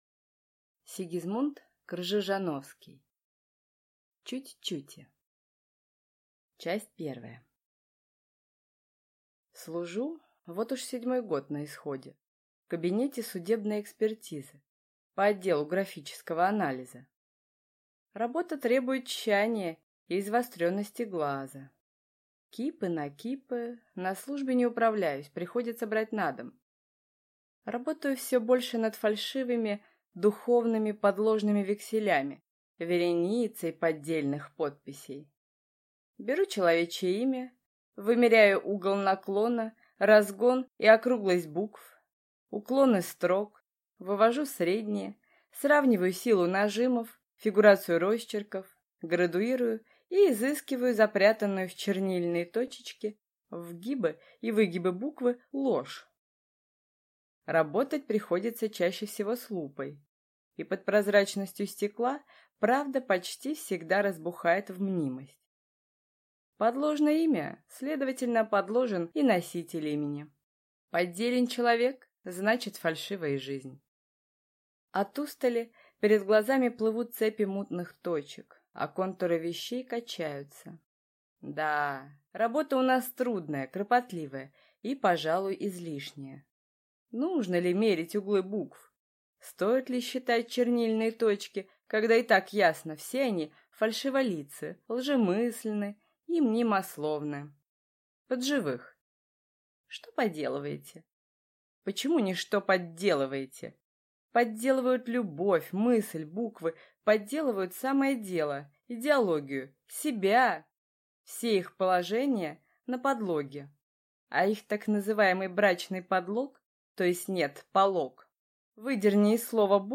Аудиокнига Чуть-чути | Библиотека аудиокниг